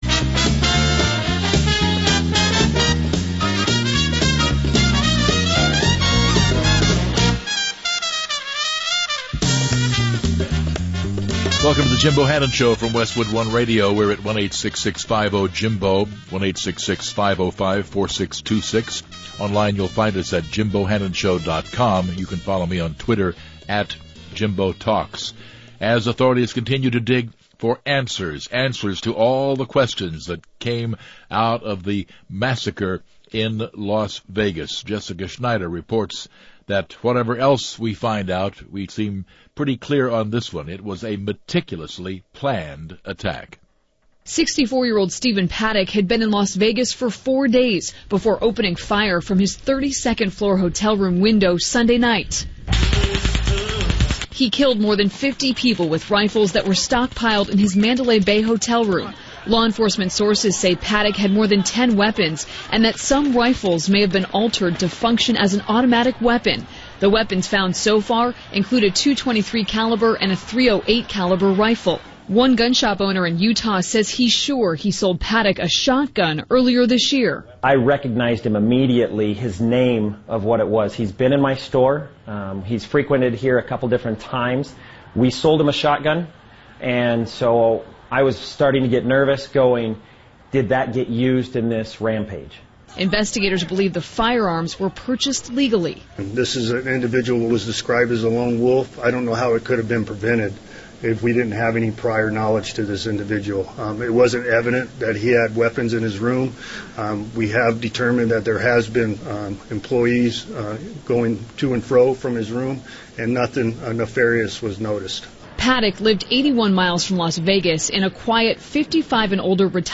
Dr. John Lott talked to Jim Bohannon about the push for new gun control and some of the myths about guns in the wake of the Las Vegas shooting.